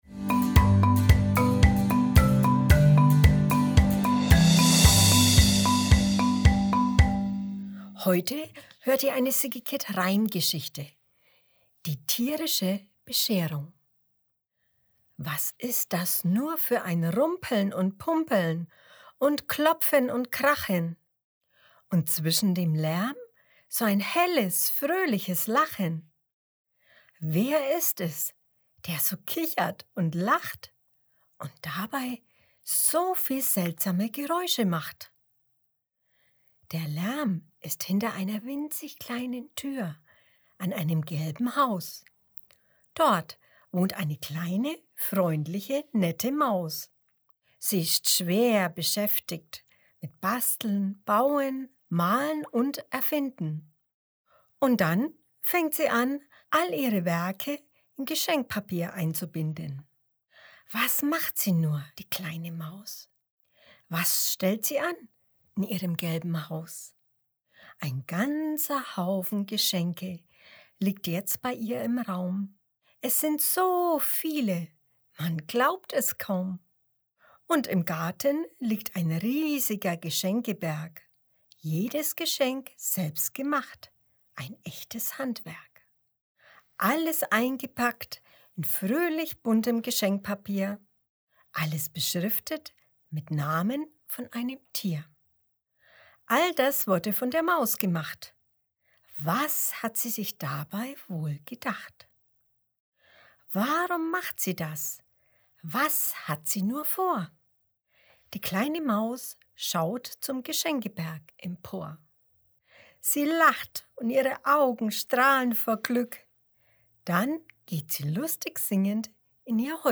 Dezember 2022 Kinderblog Jahreszeiten, Winter, Reime, Vorlesegeschichten Was ist das nur für ein Rumpeln und Pumpeln und Klopfen und Krachen?